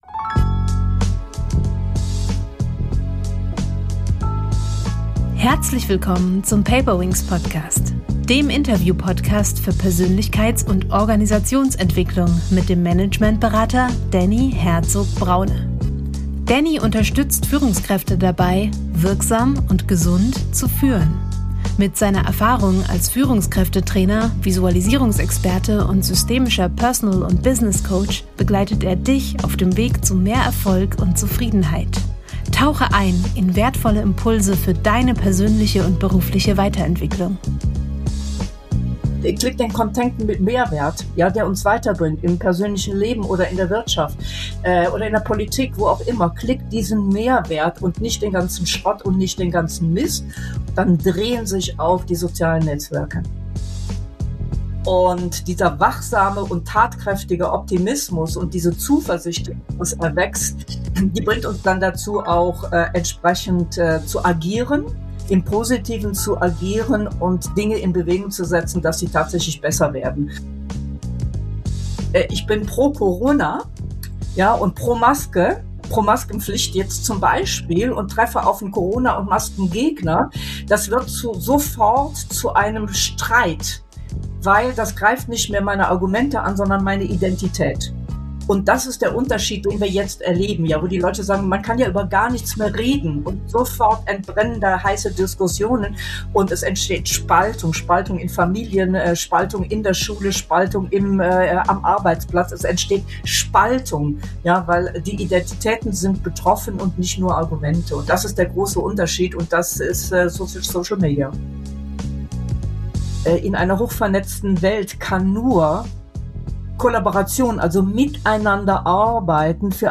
Ein Gespräch voller Impulse für alle, die mehr Sinn und Wirksamkeit suchen.